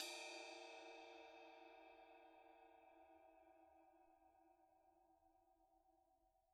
susCymb1-hitstick_pp_rr1.wav